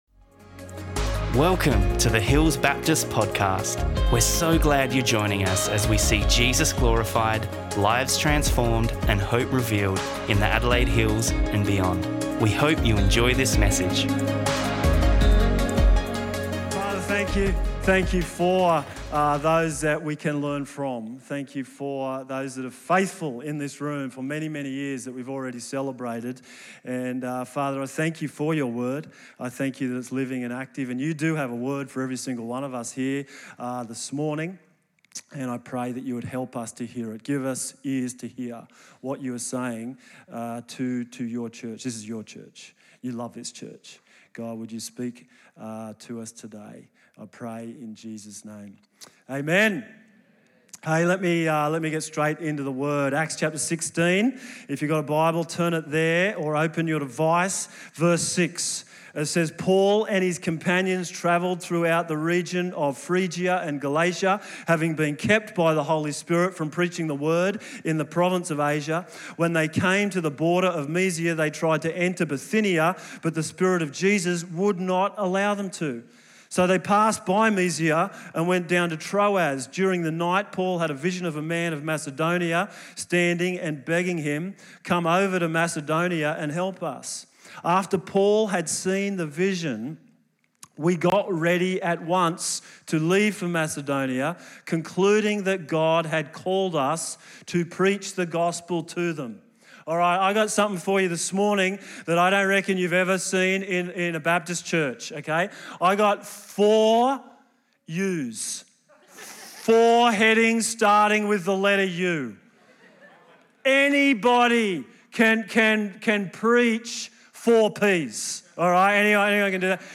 5 U's sermon.mp3